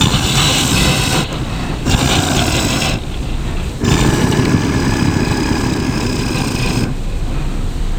Cronus_Roar.wav